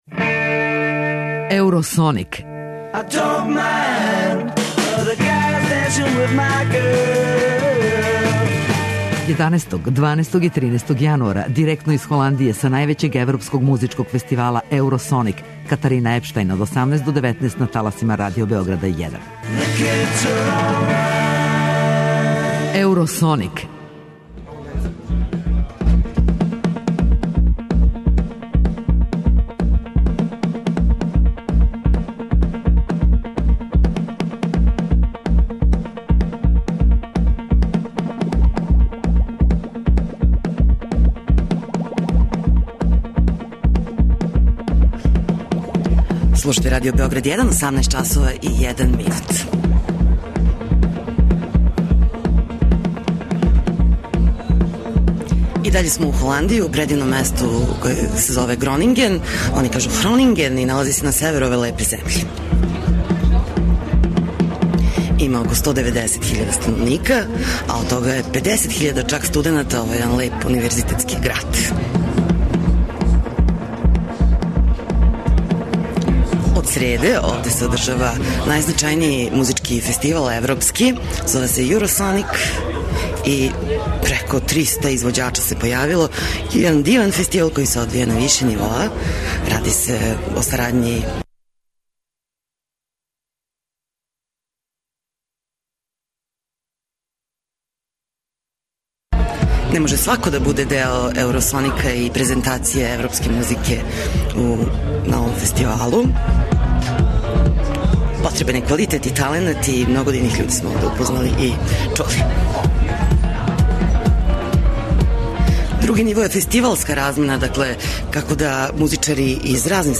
Радио Београд извештава уживо из Гронингена у Холандији, где се одржава један од најзначајнијих музичких фестивала у свету: Еуросоник. Овај јединствени фестивал не доводи глобалне комерцијалне звезде већ тражи нови квалитет у европској музици, а прати га и највеће окупљање стручњака из света музике.